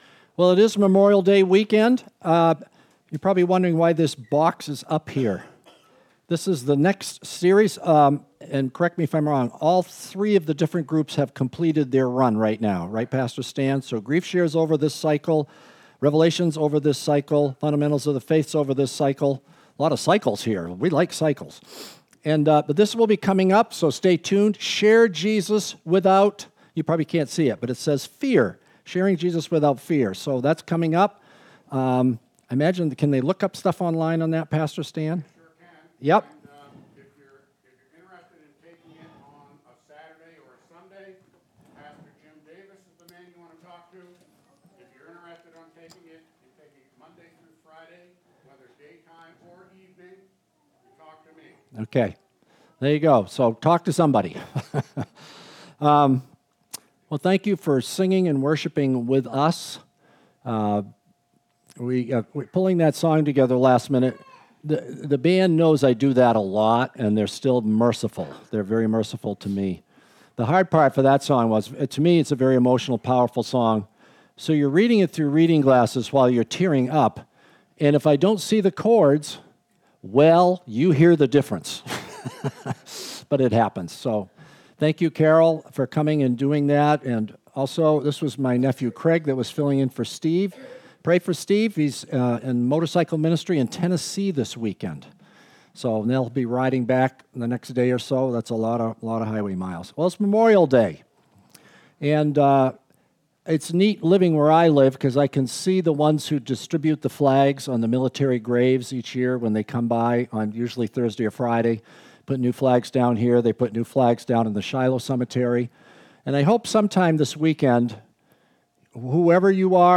Sunday May 26, 2024, Worship Service: Memorial Day